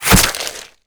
wav / general / combat / abilities / smallclaw / flesh2.wav
flesh2.wav